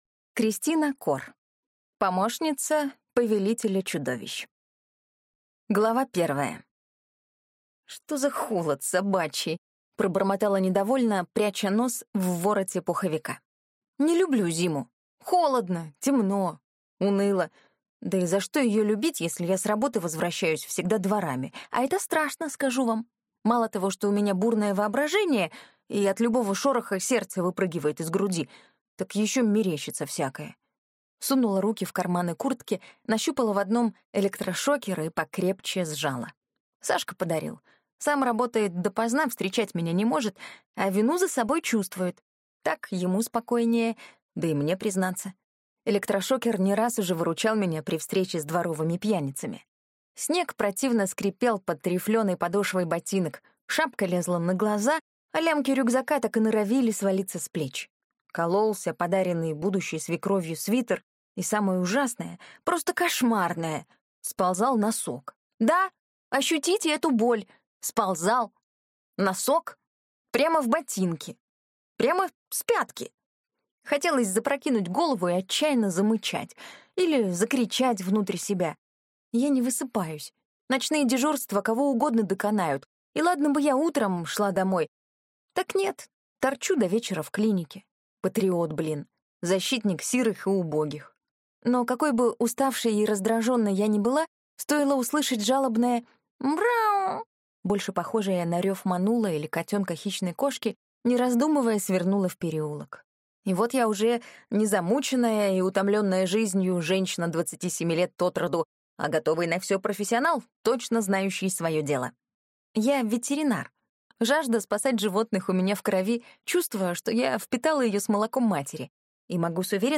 Аудиокнига Помощница Повелителя чудовищ | Библиотека аудиокниг